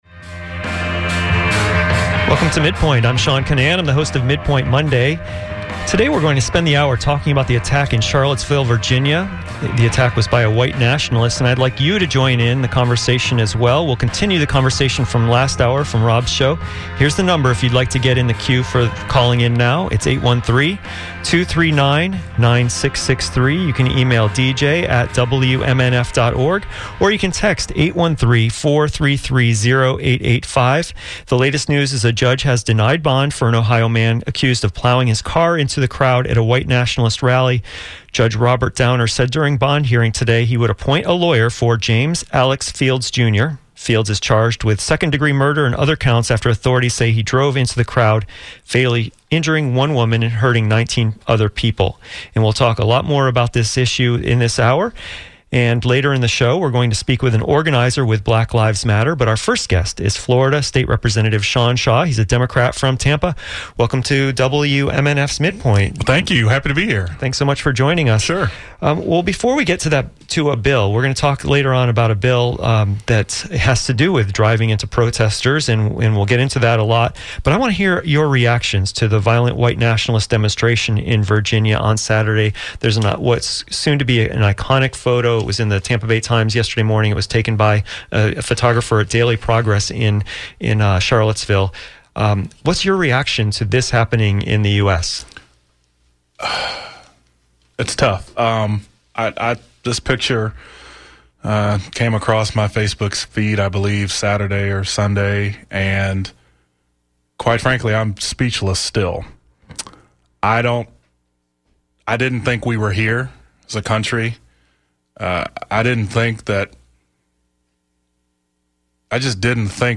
Another was Florida State Representative Sean Shaw, a Democrat from Tampa. One thing we talked about was a bill in the Florida Legislature that would have protected drivers who kill protesters.